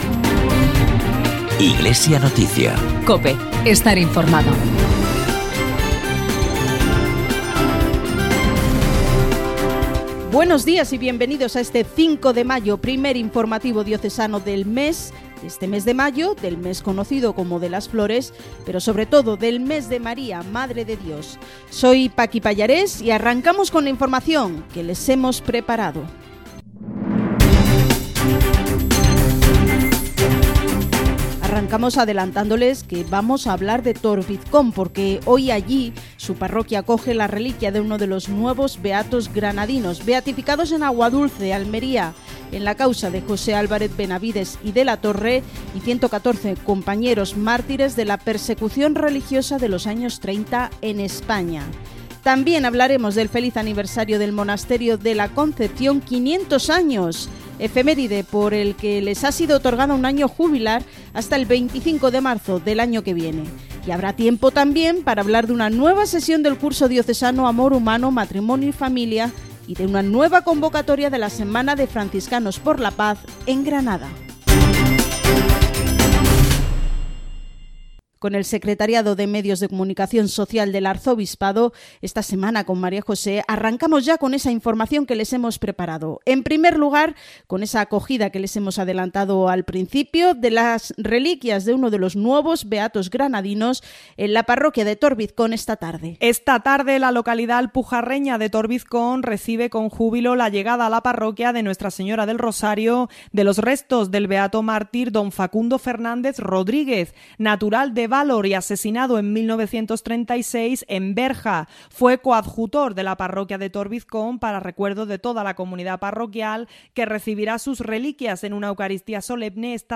En el informativo diocesano “Iglesia Noticia”, emitido hoy 5 de mayo en COPE Granada.